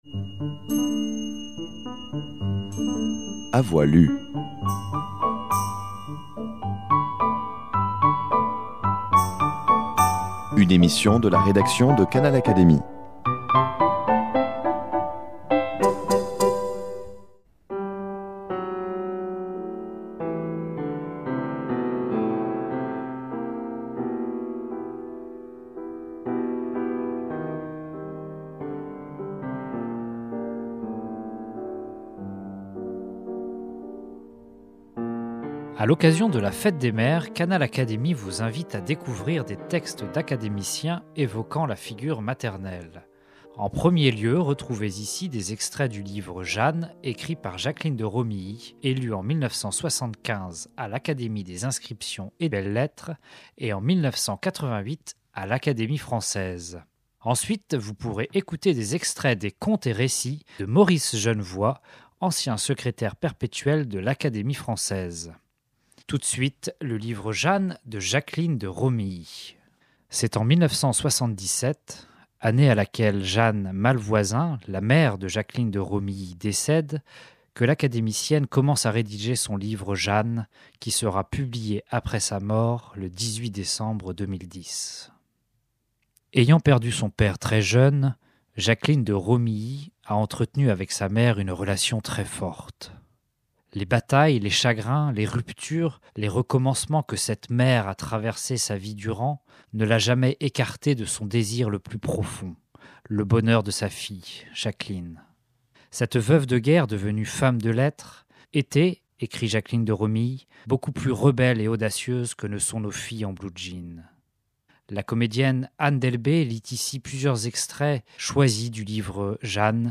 Jacqueline de Romilly, Jeanne, Académie française, maman, mère, fête, lecture, livre, Académie des inscriptions et des belles-lettres